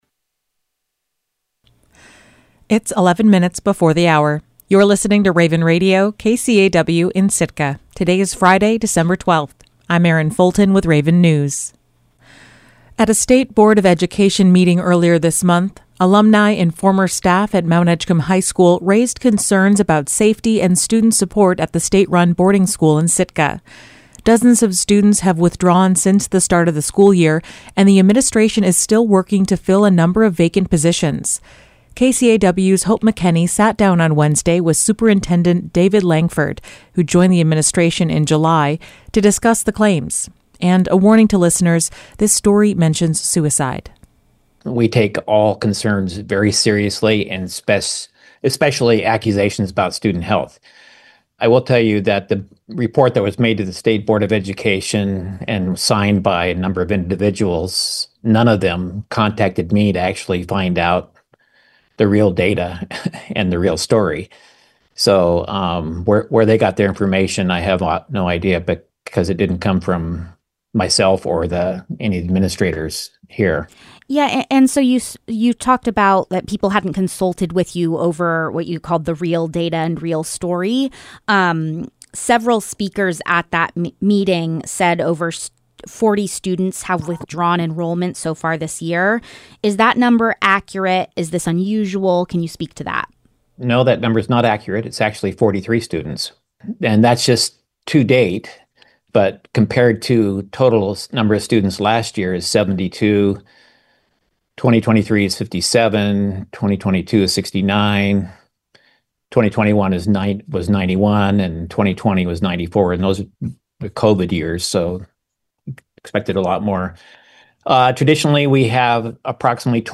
Newscast.mp3